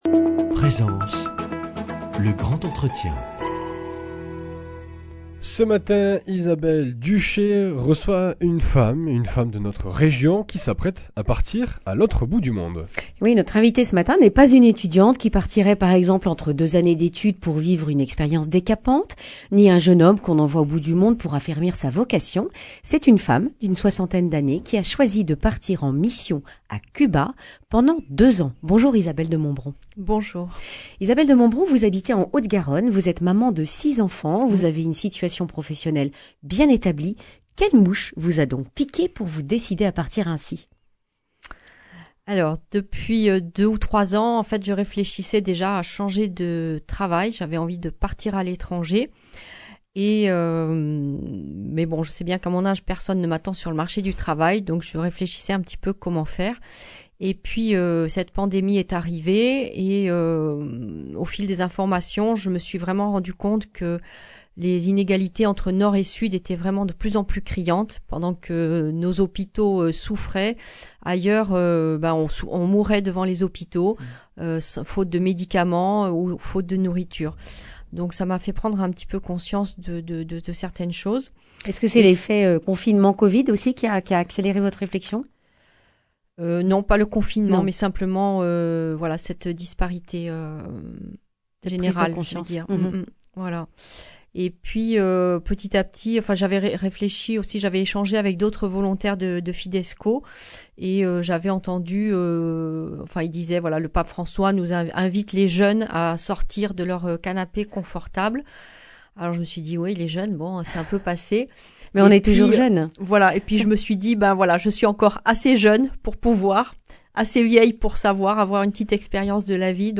Accueil \ Emissions \ Information \ Régionale \ Le grand entretien \ Partir deux ans en mission à Cuba à soixante ans, c’est possible !